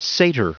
Prononciation du mot satyr en anglais (fichier audio)
Prononciation du mot : satyr